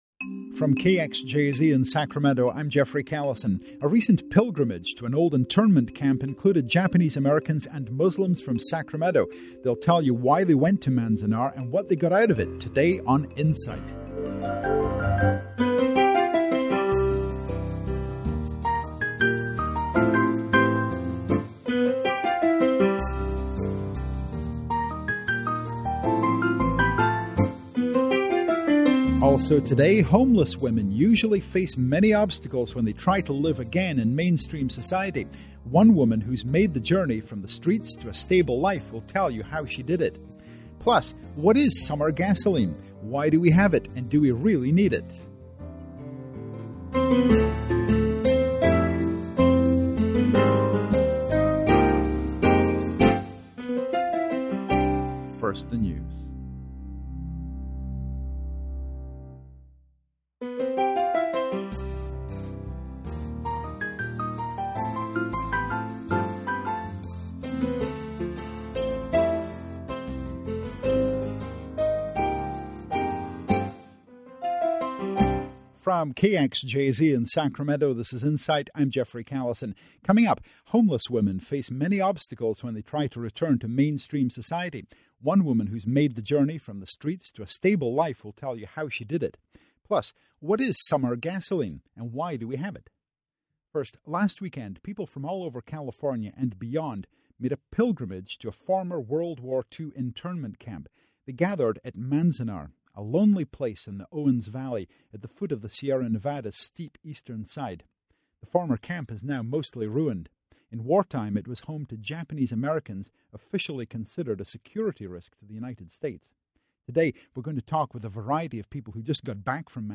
Manzanar Pilgrimage Attendees Featured On Sacramento Public Radio Show